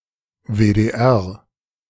Westdeutscher Rundfunk Köln (pronounced [ˈvɛstˌdɔʏtʃɐ ˈʁʊntfʊŋk ˈkœln]; "West German Broadcasting Cologne"), shortened to WDR (pronounced [ˌveːdeːˈʔɛʁ]